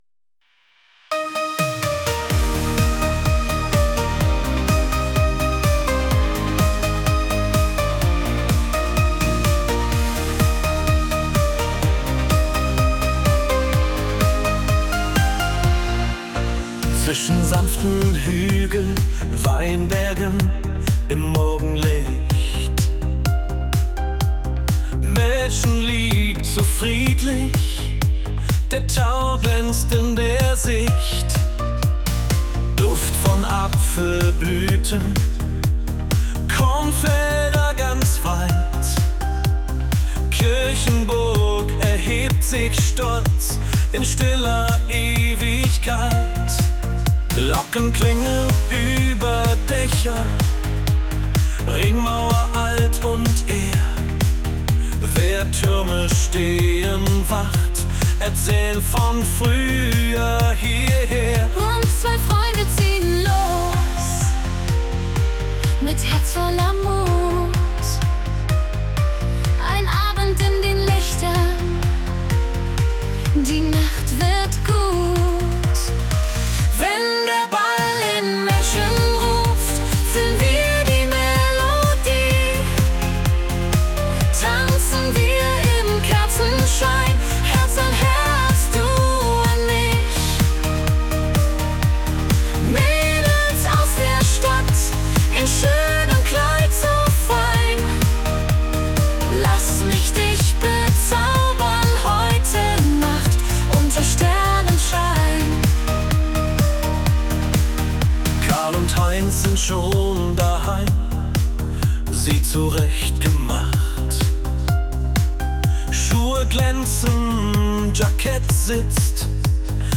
Schlager
• KI-generierte Melodie